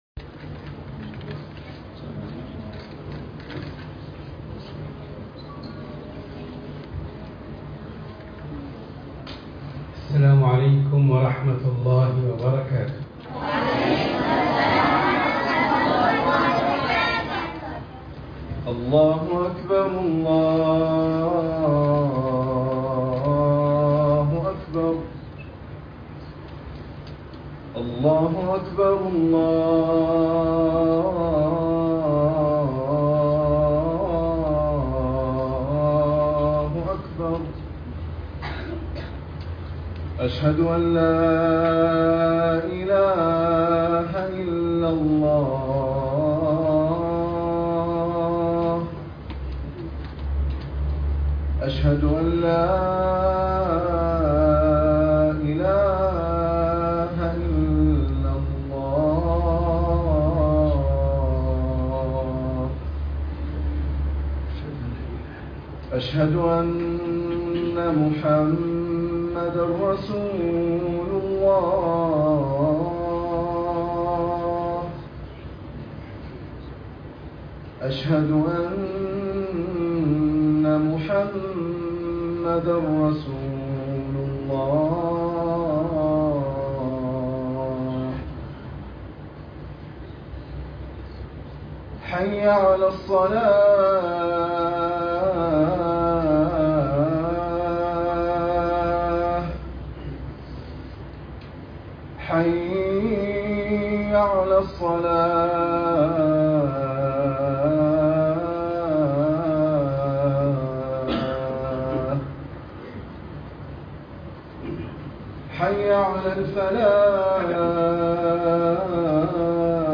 خطبة الجمعة اليوم بعنوان( مع النبي محمد صلى الله عليه وسلم